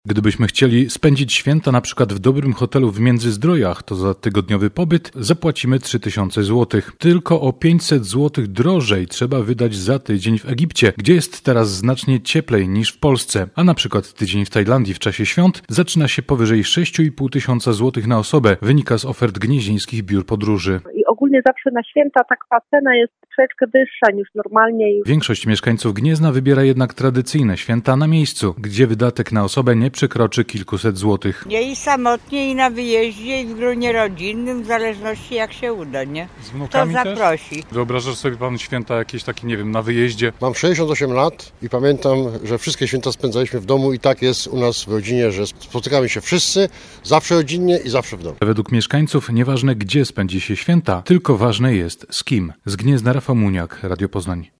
- zapewniał naszego reportera jeden z przechodniów.